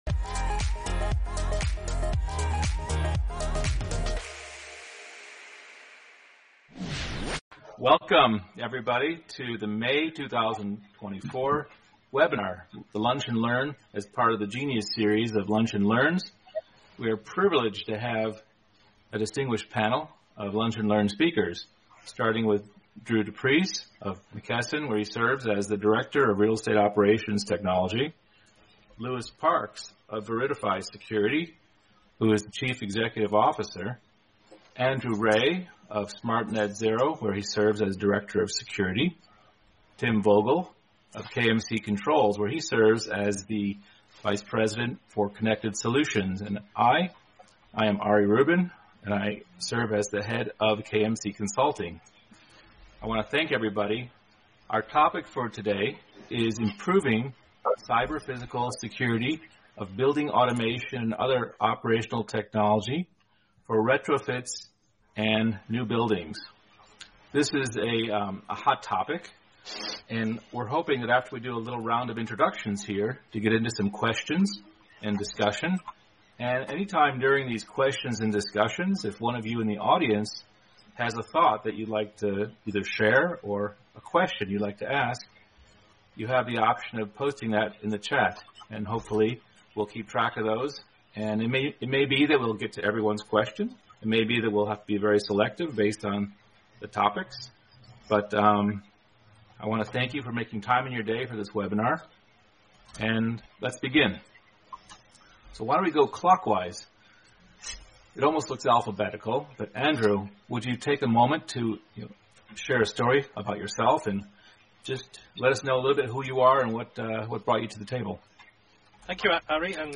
This is the podcast audio, highlights, and key insights from a Lunch and Learn session about the importance of improving cyber-physical security in building automation systems, highlighting the challenges and solutions in the industry.